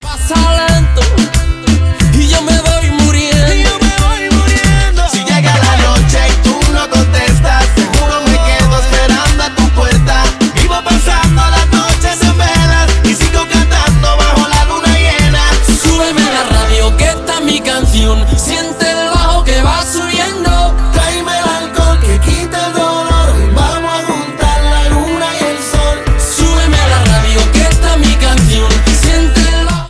• Pop Latino